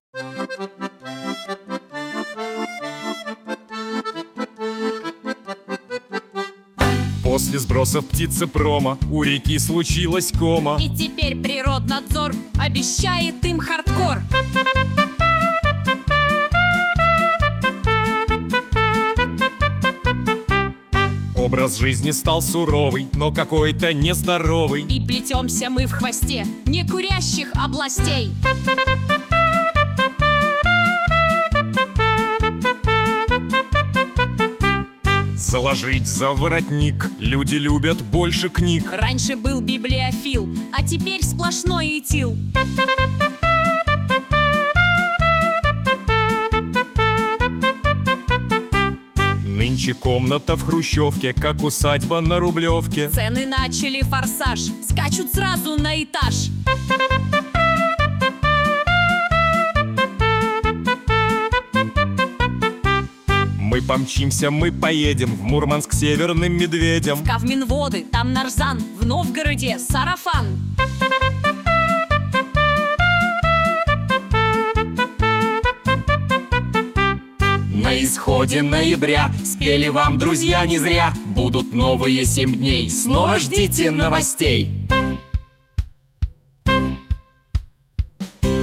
О главных и важных событиях — в виде комических куплетов